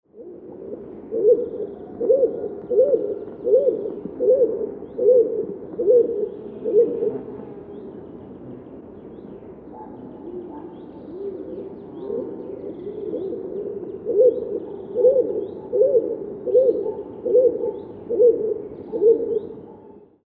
Wer singt oder ruft hier?
Vogel 9
Die Tierstimmen sind alle aus dem Tierstimmenarchiv des Museum für Naturkunde - Leibniz-Institut für Evolutions- und Biodiversitätsforschung an der Humboldt-Universität zu Berlin
MH12_Vogel9.mp3